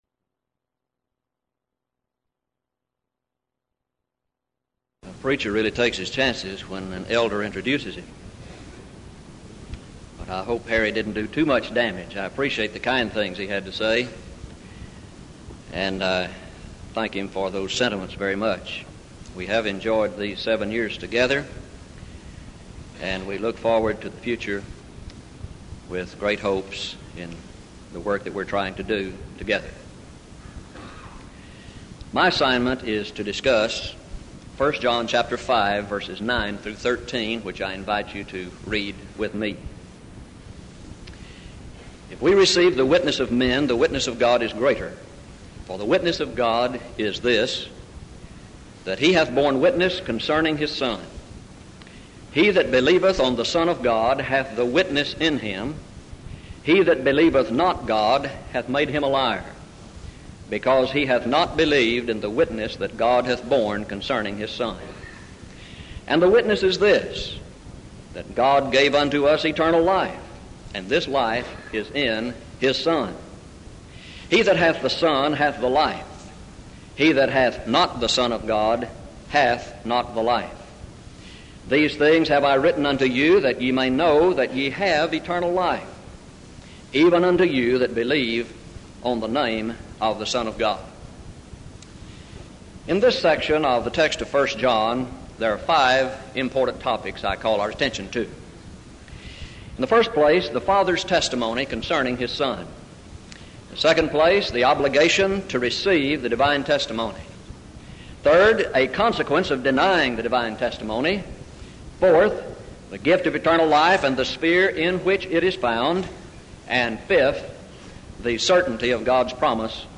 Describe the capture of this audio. Event: 1987 Denton Lectures Theme/Title: Studies In I, II, III John